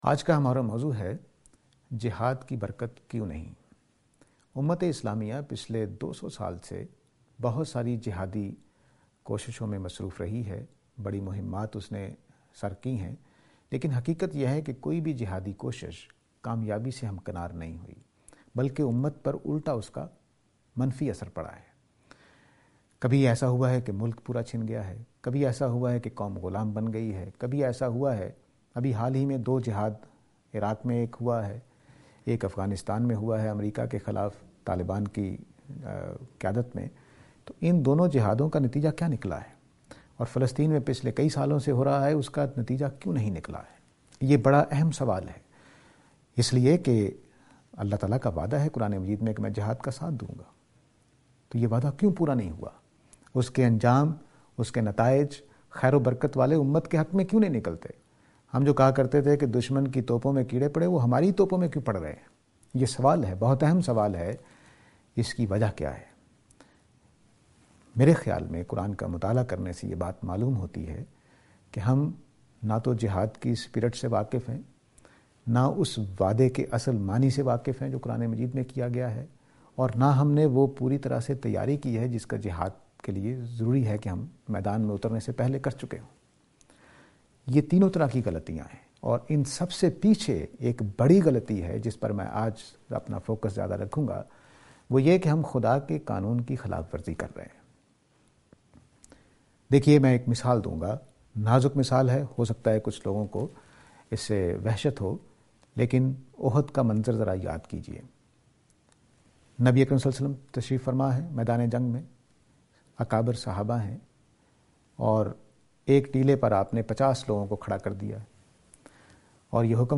This lecture is and attempt to answer the question "Erroneous Concept of Jihad".